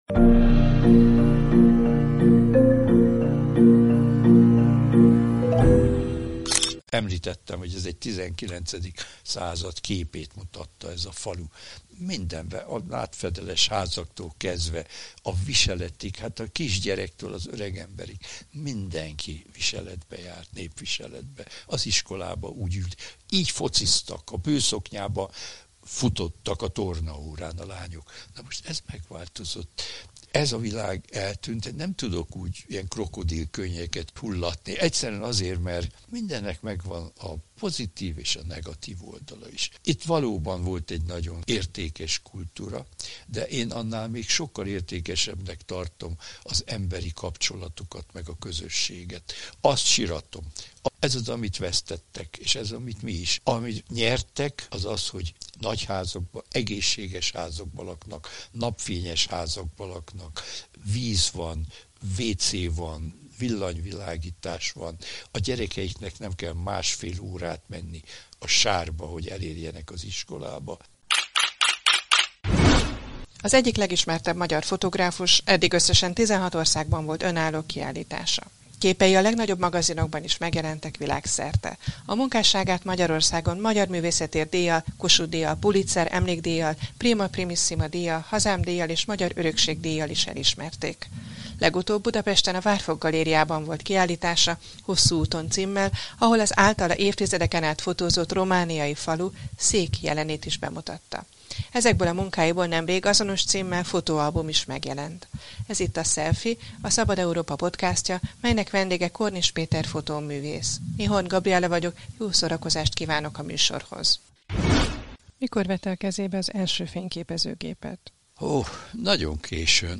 Legutóbbi kiállításán az általa évtizedeken át fotózott romániai falu, Szék jelenét is bemutatta. A Szelfi vendége Korniss Péter fotóművész volt.